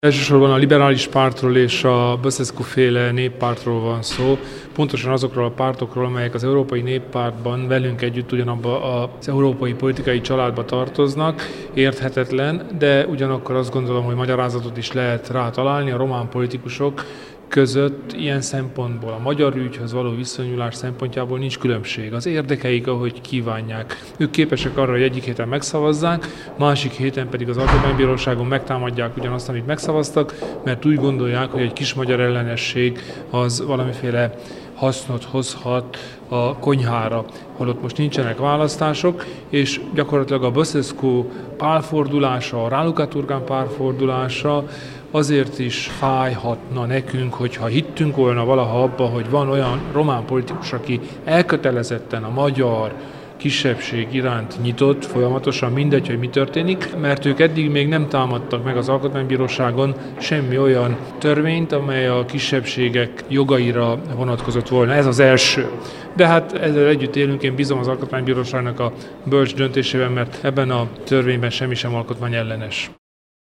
Az RMDSZ elnöke a Szövetségi Képviselők tanácsa előtt tartott politikai beszámolójában beszélt az érthetetlen pálfordulásról.